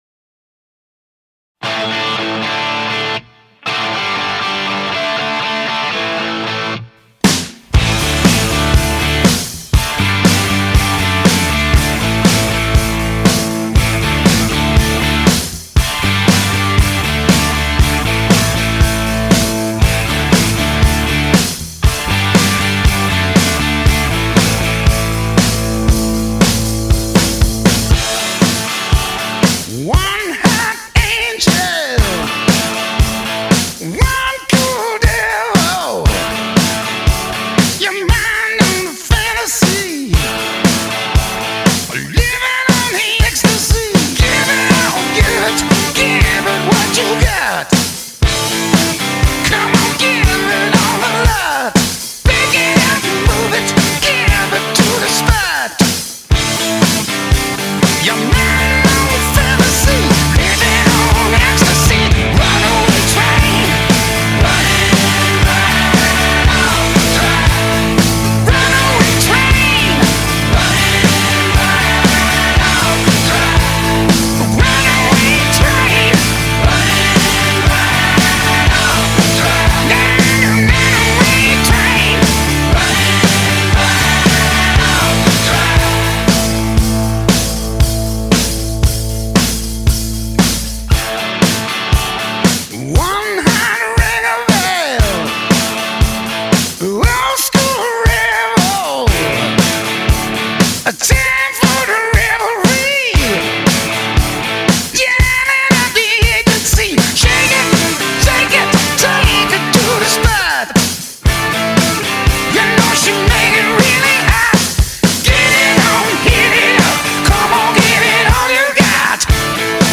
I love the gang vocals on the chorus and the guitar riff.